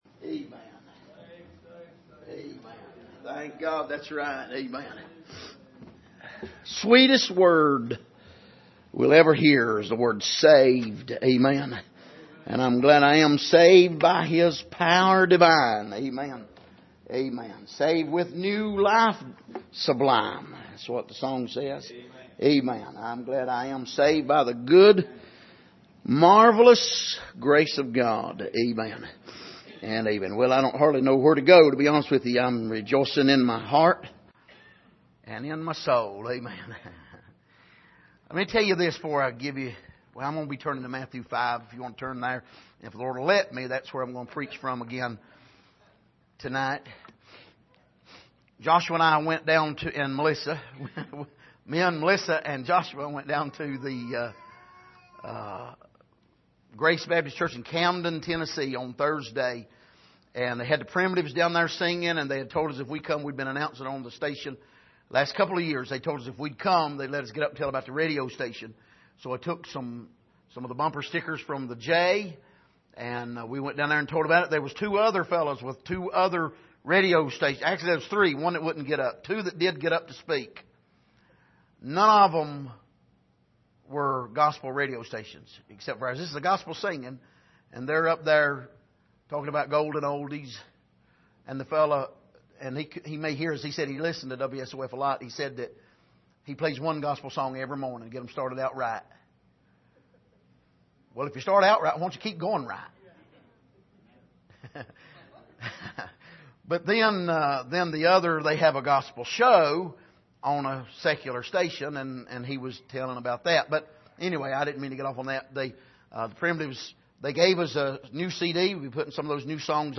Passage: Matthew 5:13-16 Service: Sunday Evening